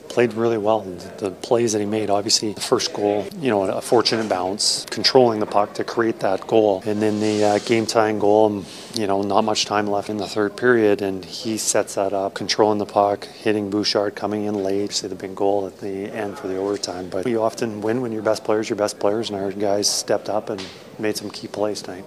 Head Coach Kris Knoblauch spoke to media following the victory discussing Draisaitl’s contributions with his 3 points, on his birthday.